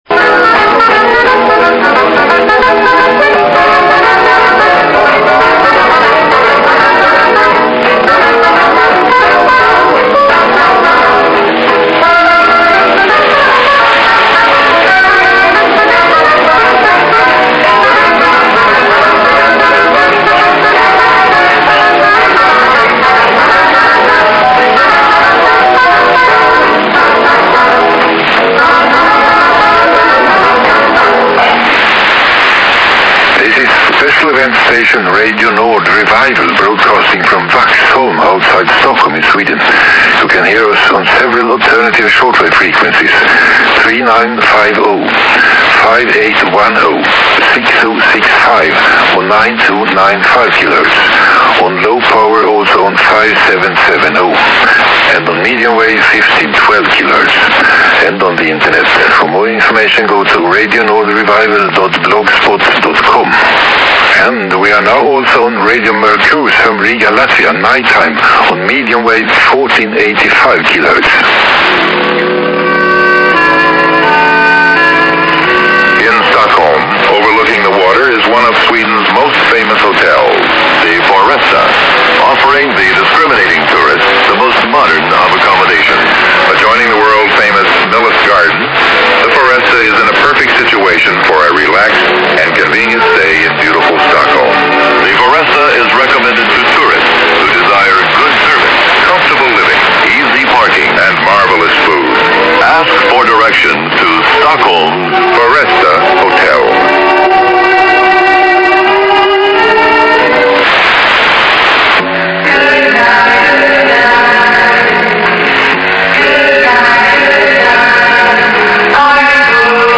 Radio Nord Revival - 2014, Station ID, Program Audio